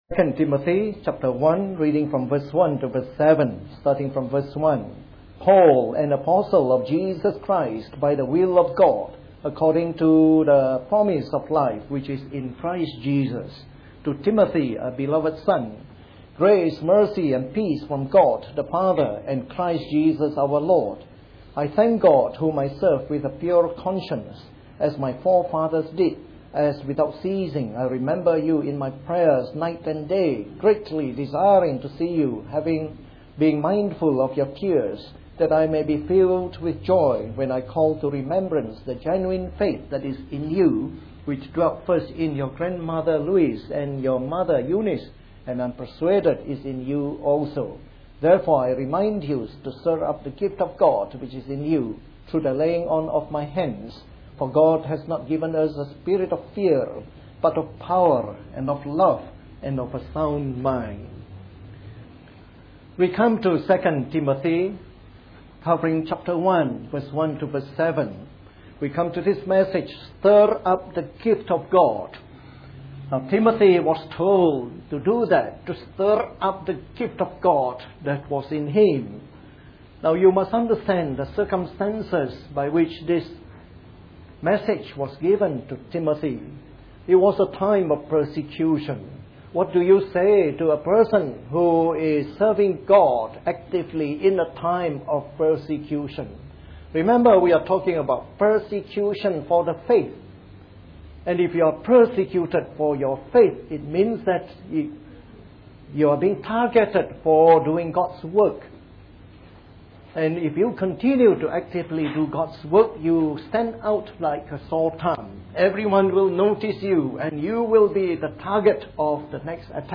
A sermon in the morning service from our series on 2 Timothy.